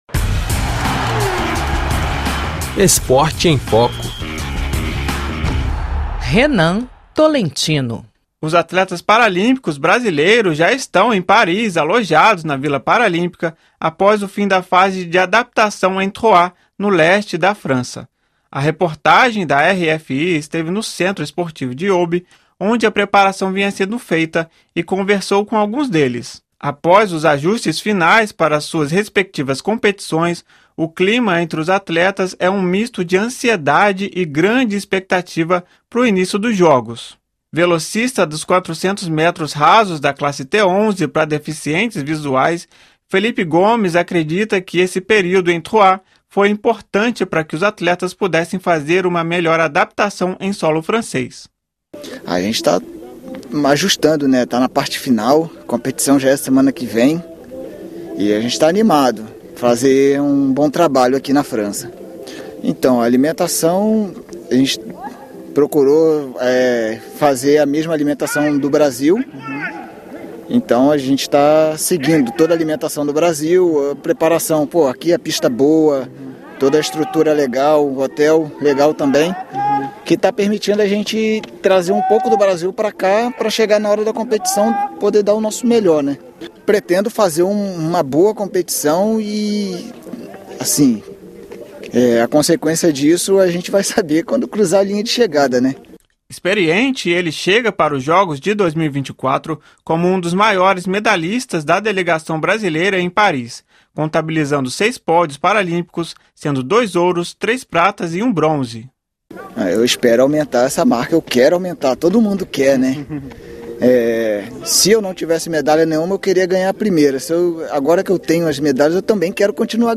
Os paratletas brasileiros já estão em Paris, alojados na Vila Paralímpica, após o fim da fase de adaptação em Troyes, no leste da França. A reportagem da RFI esteve no Centro Esportivo de Aube, onde a preparação vinha sendo feita, e conversou com alguns deles.